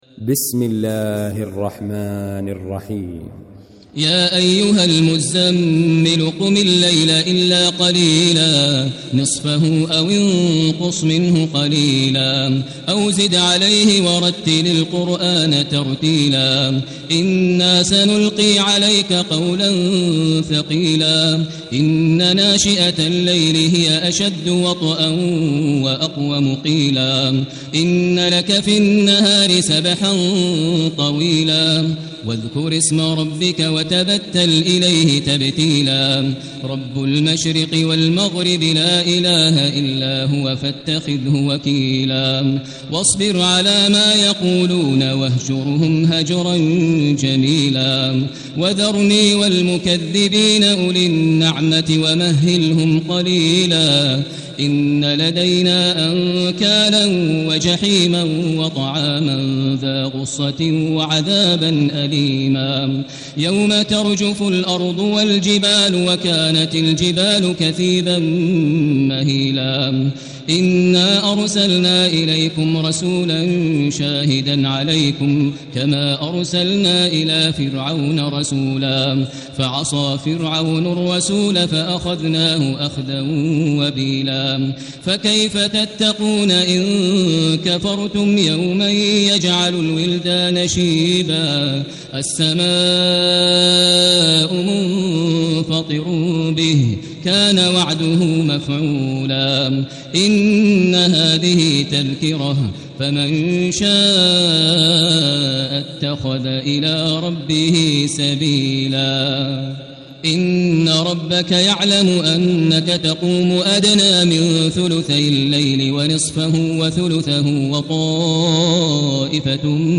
المكان: المسجد الحرام الشيخ: فضيلة الشيخ ماهر المعيقلي فضيلة الشيخ ماهر المعيقلي المزمل